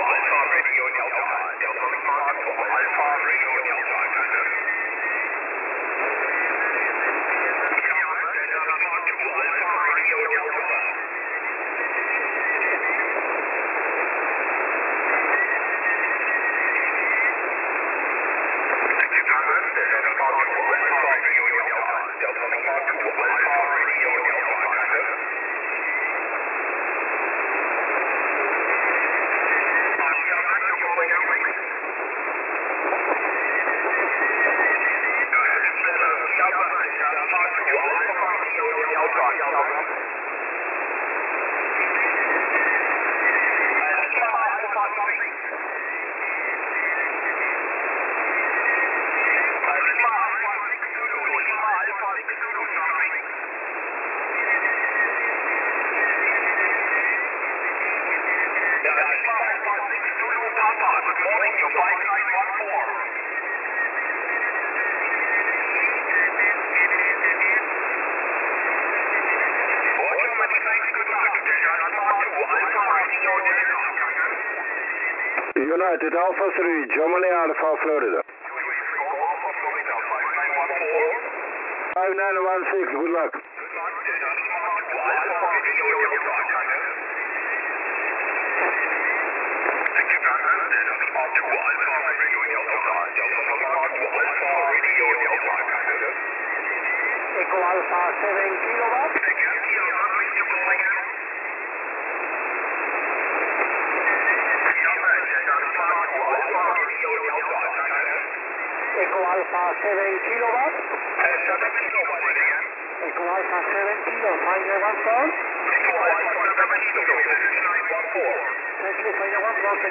WEB-SDR Aufnahmen von verschieden Standorten